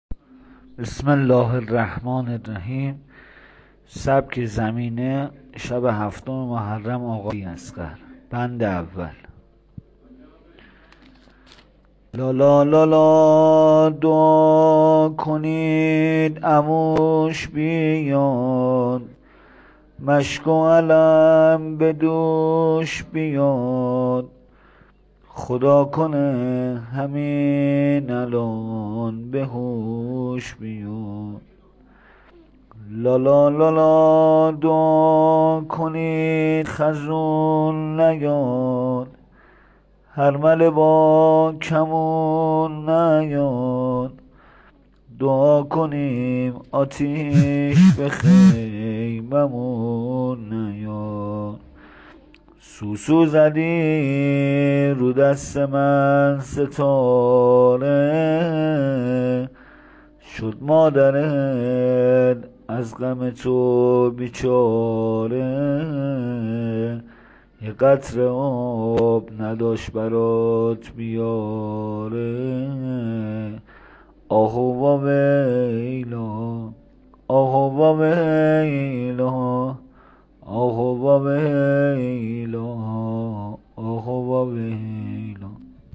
سبک زمزمه ی ما بین روضه....  شب سوم محرم...